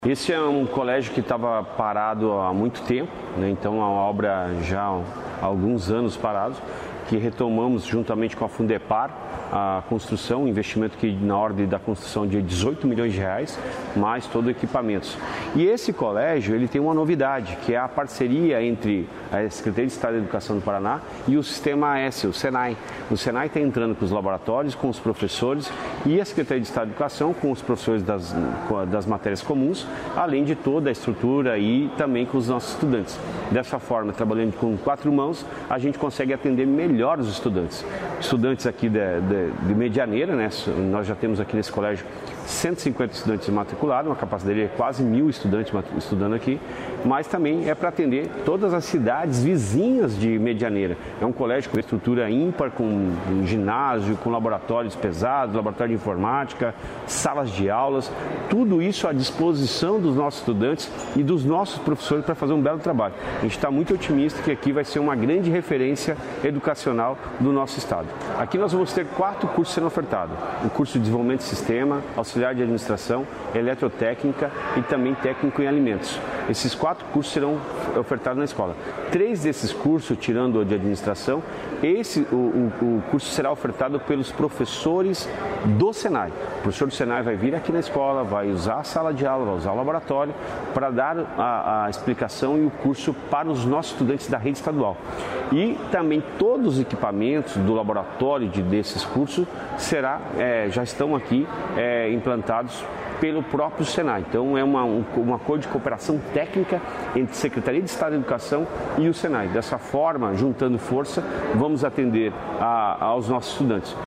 Sonora do secretário da Educação, Roni Miranda, sobre o novo CEEP em Medianeira